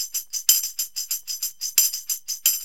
TAMB LP 92.wav